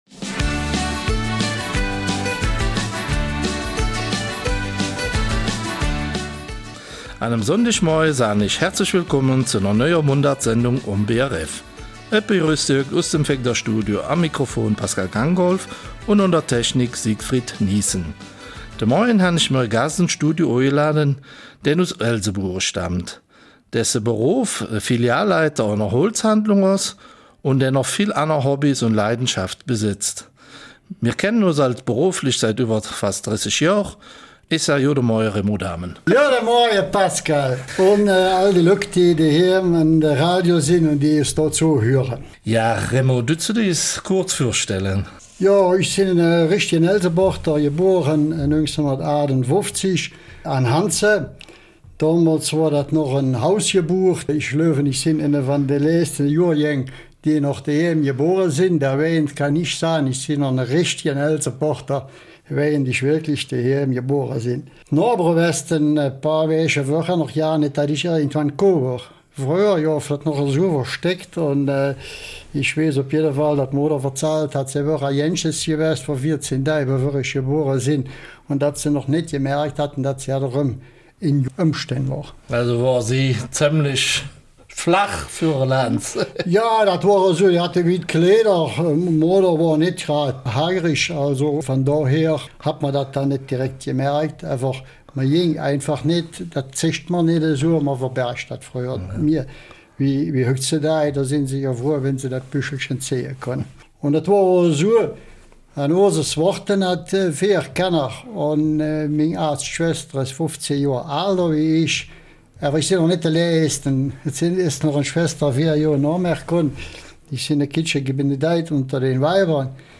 Eifeler Mundart: Einblicke in den Holzalltag